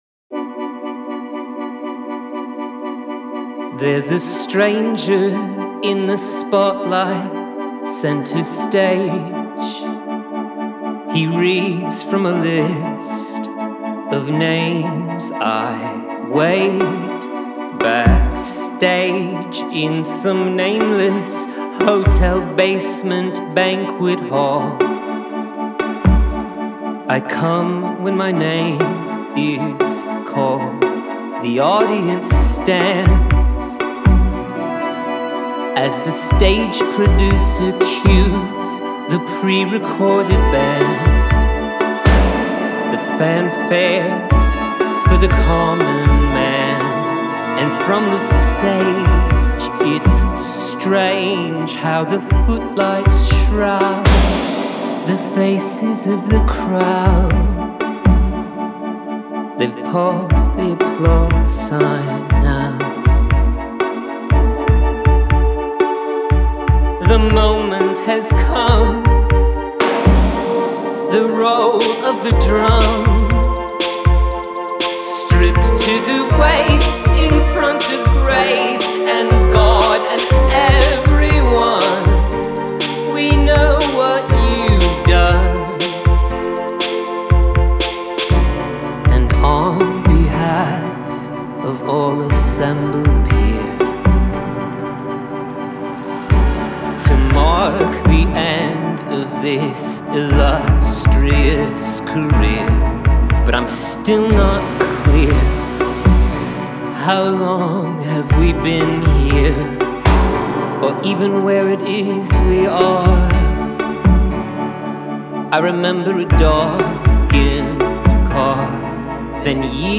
Guitars, Contrabass, Atmosphere
Piano
Rhythm Programming